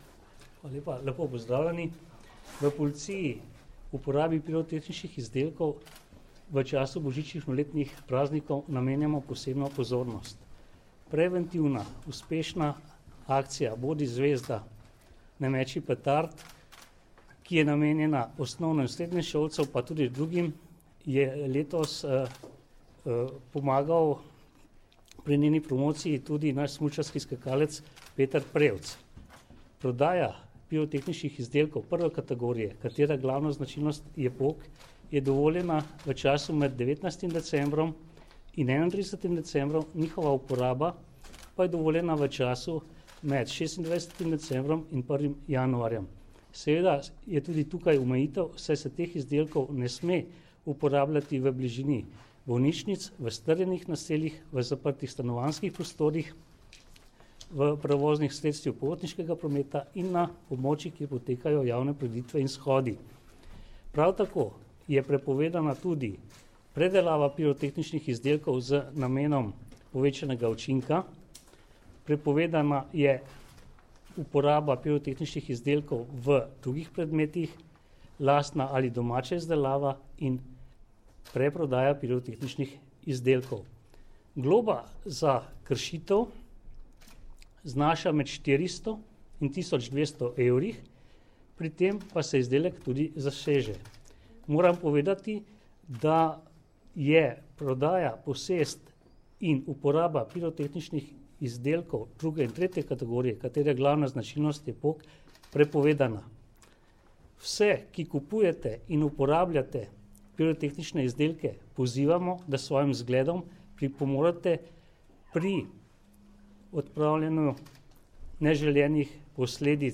Letošnjo akcijo Bodi zvezda, ne meči petard, pa tudi različne vidike uporabe pirotehnike ter nevarnosti in neprijetnosti, ki jih ta lahko povzroča, so na današnji novinarski konferenci predstavili: